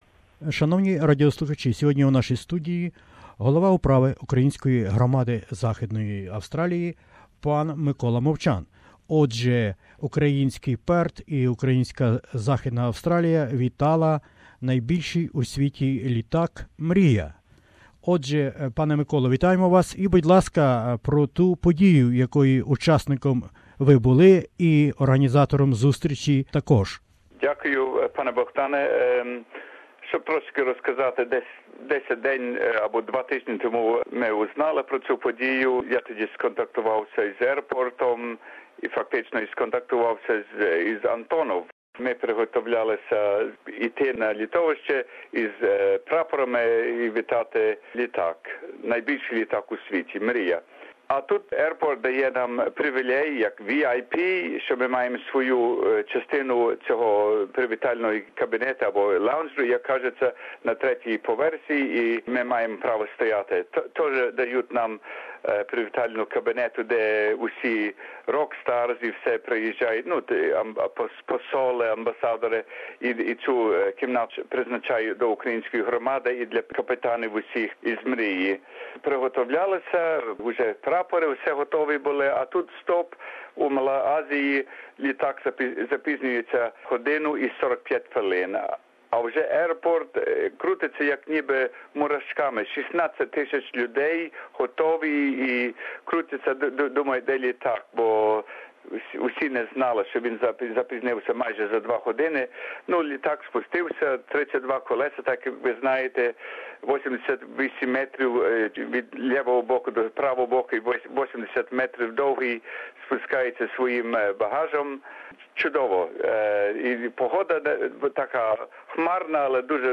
SBS Ukrainian